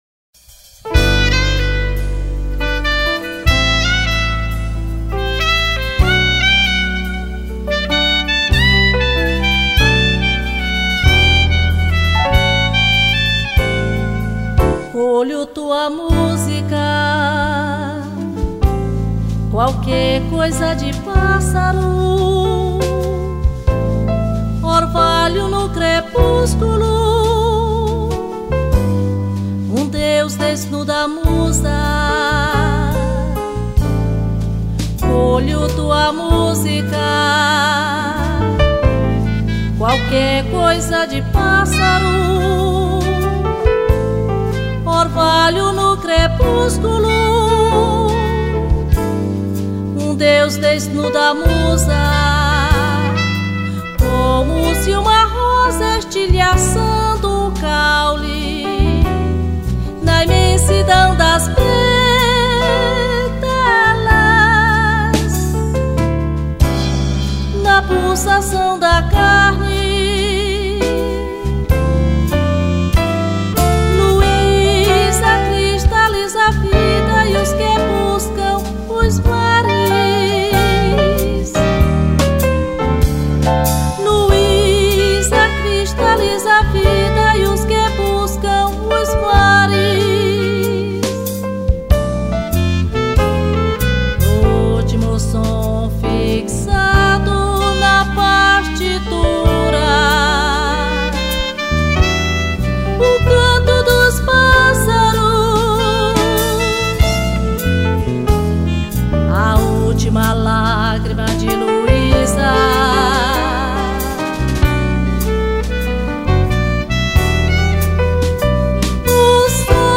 311   04:23:00   Faixa:     Jazz